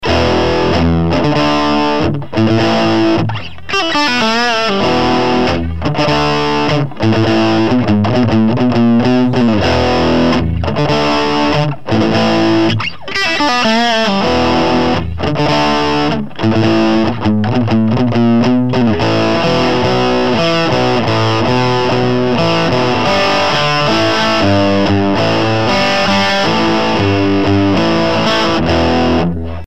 Четыре режима-звучания - lead-high, lead-low, clean-high, clean-low.
Примеры звучания в кабинет с Celestion Rocket50, микрофон genius в микрофонный вход звуковой карты, без обработок.
sovtek_engl_lead_low.mp3